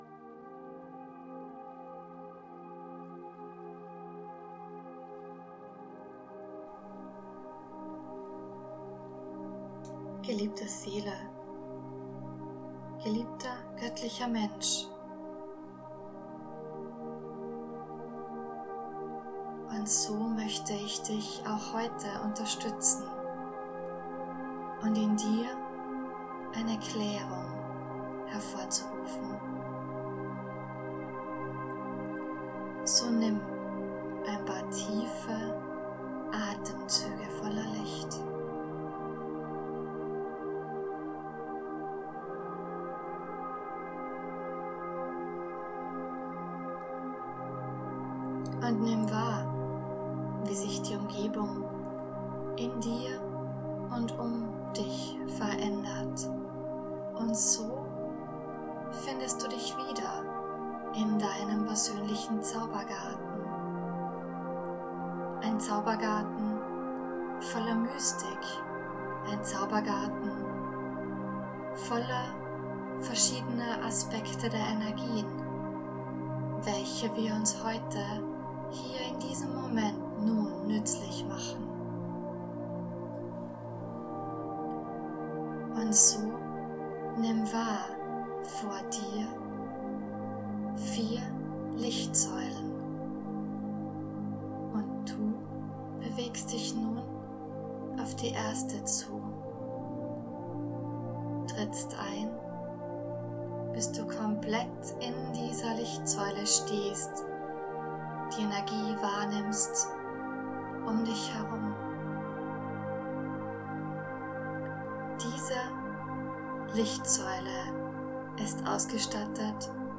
Meditationen/Channelings
Meditation-Zaubergarten-Reinigung-Erloesung-Erdung-Erfuellung.mp3